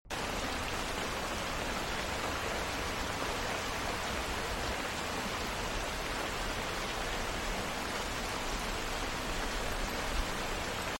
One rainy day rain sound effects free download
One rainy day - rain ASMR